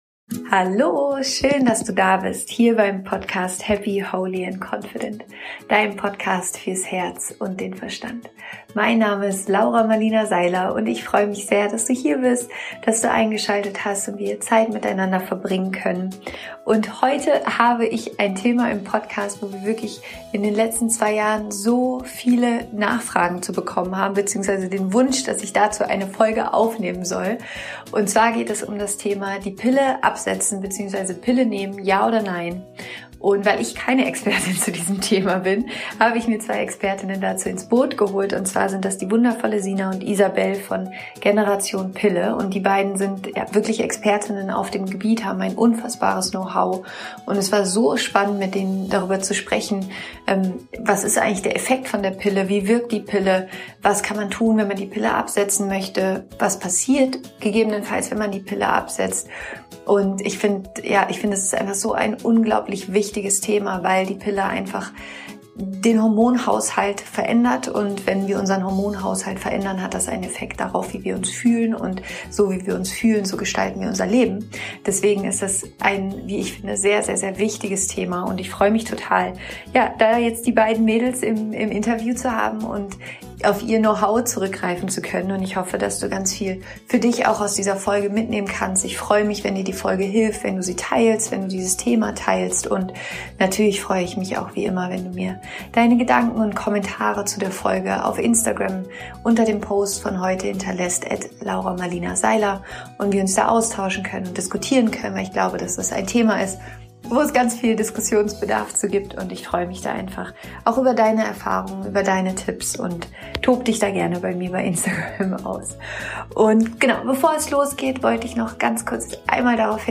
Du erfährst in diesem Interview: